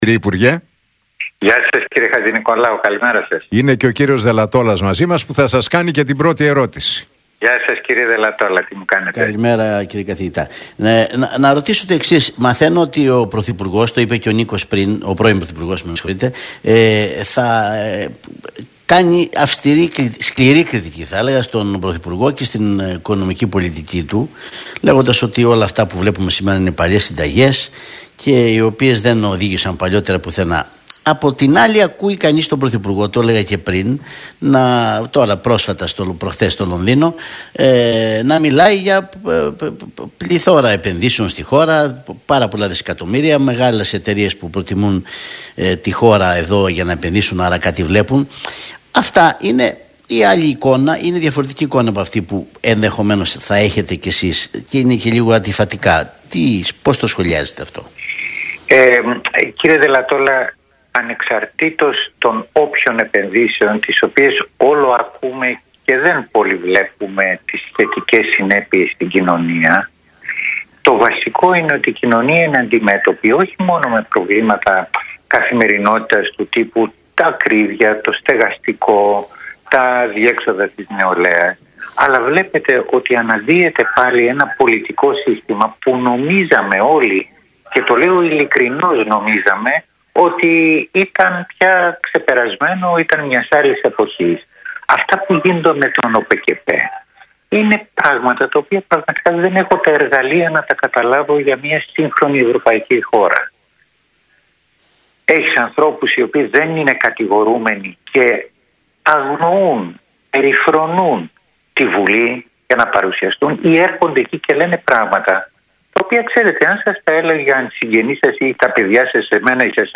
Ο πρώην υπουργός Κώστας Γαβρόγλου, μιλώντας στον Realfm 97,8, σχολίασε το βιβλίο του Αλέξη Τσίπρα «Ιθάκη», τονίζοντας ότι αποτελεί ευκαιρία για επαφή με